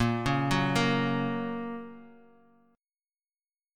Bbm#5 chord